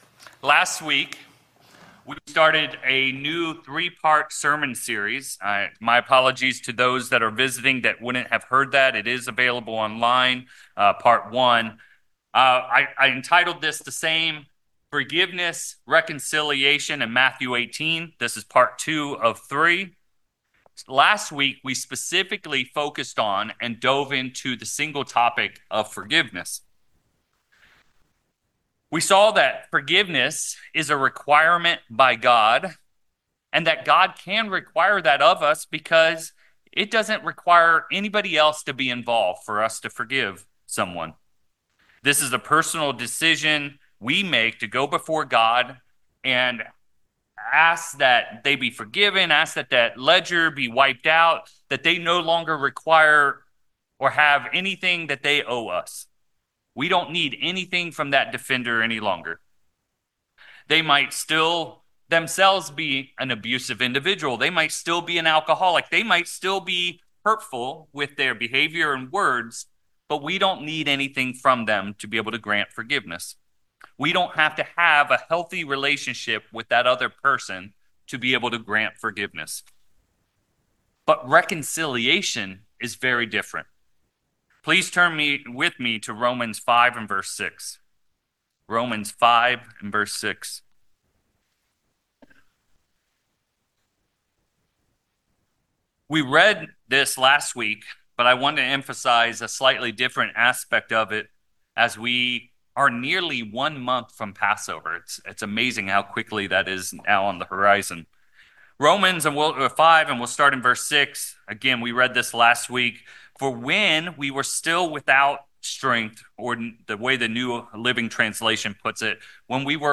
3/8/25 In this sermon, we move from forgiveness to reconciliation. We explore how reconciliation takes more than one person, and dive into why reconciliation is not required by God, and when it may be unwise or unsafe to do so. We then dive into the process for explicit reconciliation.